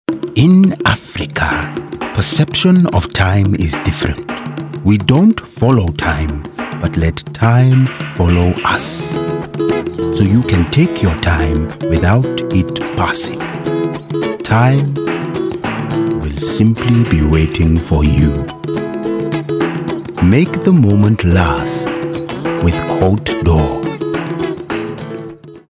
20/30's London/RP, Cool/Smooth/Gravitas
• Commercial
Cotes D’Or (African)